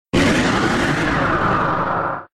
Cri de Méga-Alakazam K.O. dans Pokémon X et Y.